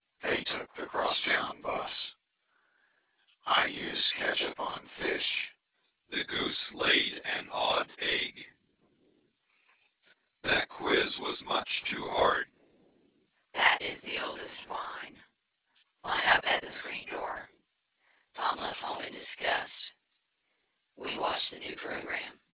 Table 2: Several examples of speech projected onto subsets of cepstral coefficients, with varying levels of noise added in the orthogonal dimensions.